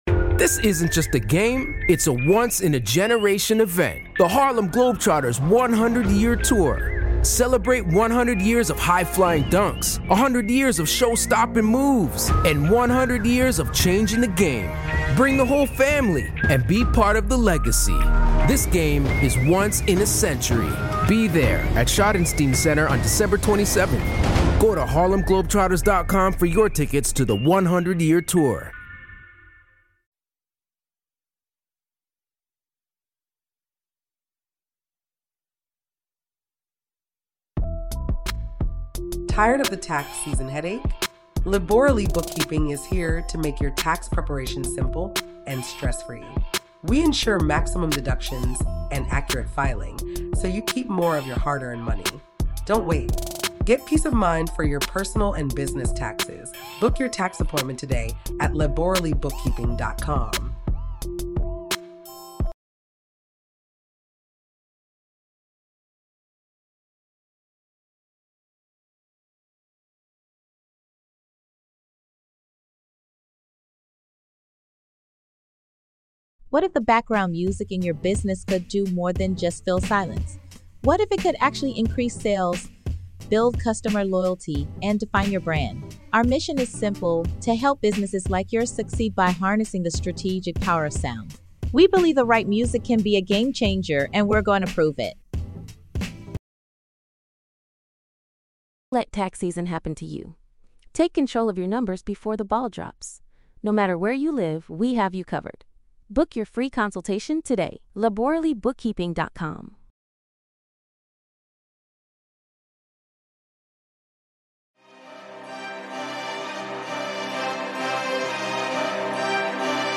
We shine a spotlight on emerging artists, bringing you exclusive interviews with the stars on the rise. Tune in for in-depth discussions on the latest sonic trends, breaking sounds, and industry movements.